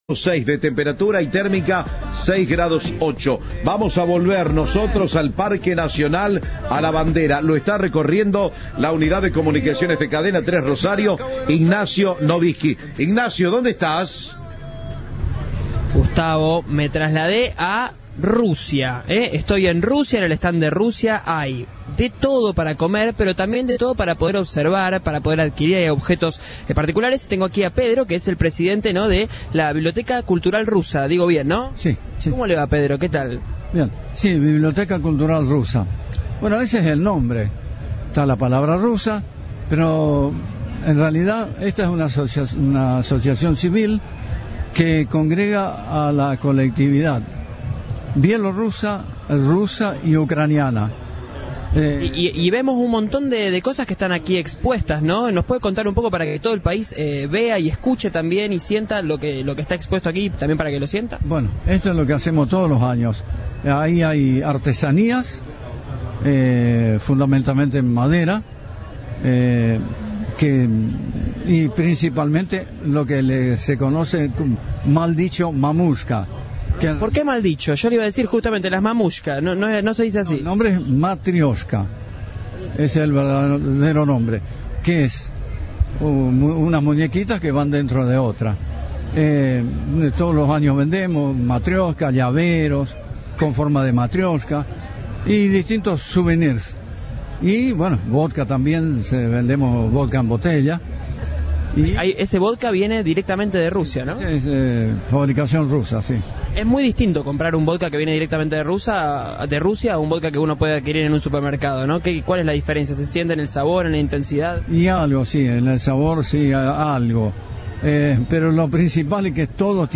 Parque de la Bandera
con una tonada típica pero un español muy claro